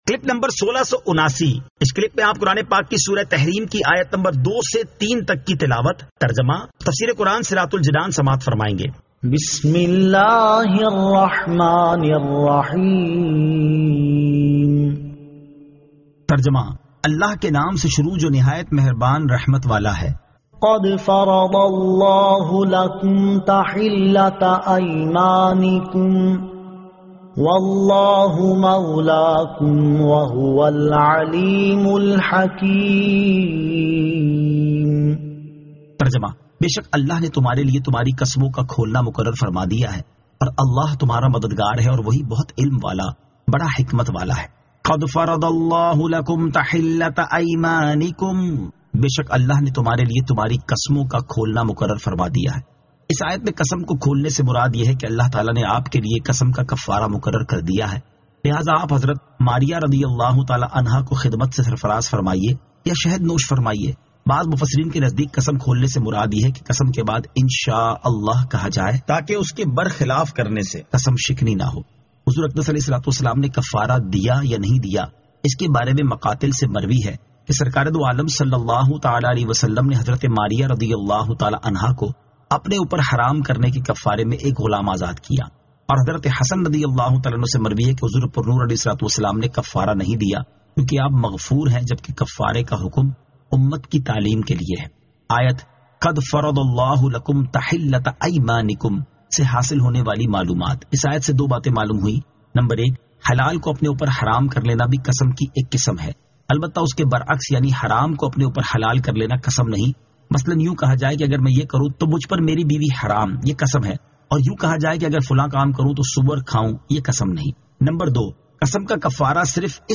Surah At-Tahrim 02 To 03 Tilawat , Tarjama , Tafseer